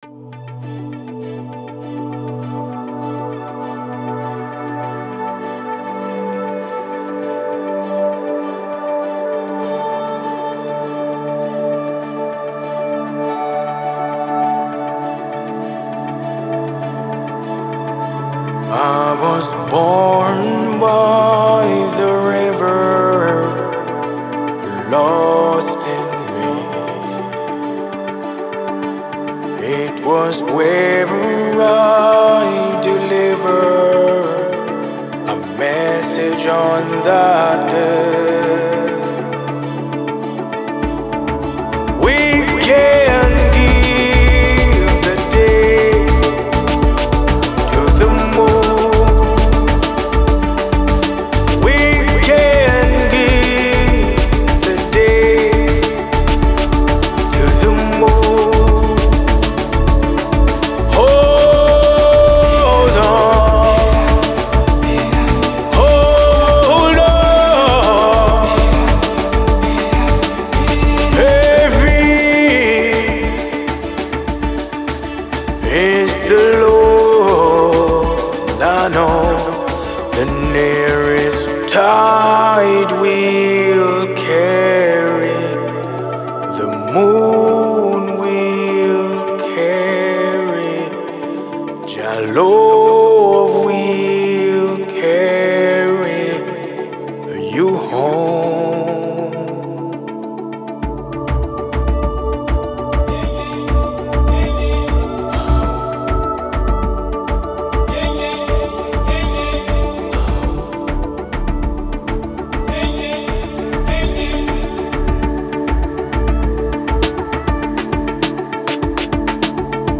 Some Jamaican Reggae Soul Music
Its a Smooth Rendition